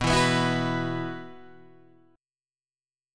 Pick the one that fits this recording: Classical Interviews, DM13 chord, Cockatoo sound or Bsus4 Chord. Bsus4 Chord